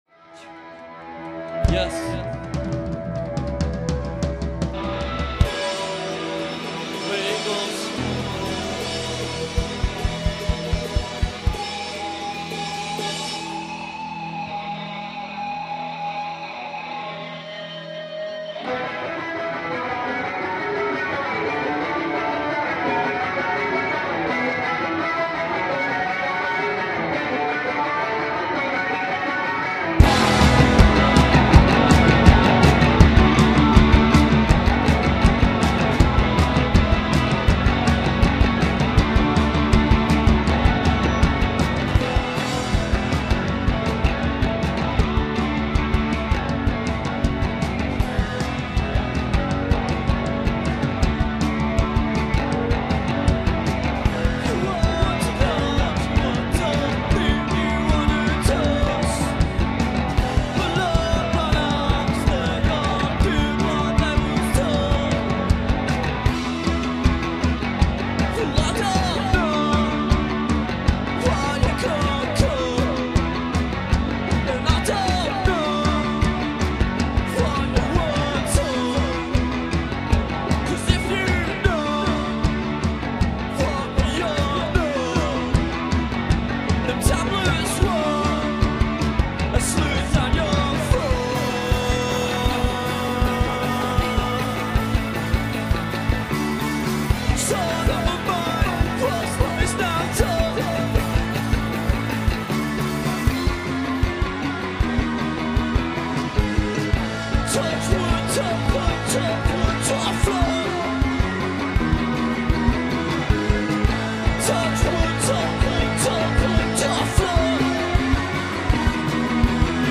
a taste of Post-Punk from Leeds . https